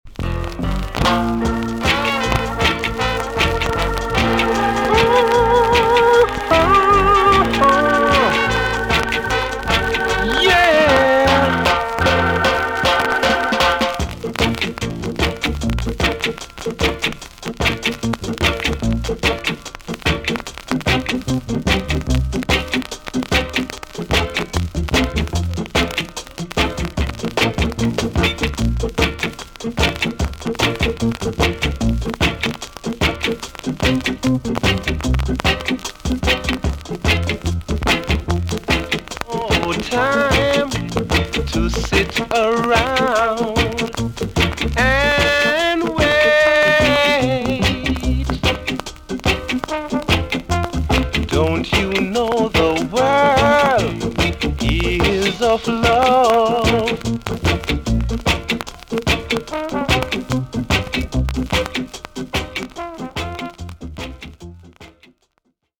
TOP >SKA & ROCKSTEADY
B.SIDE Version
VG ok 全体的にチリノイズが入ります。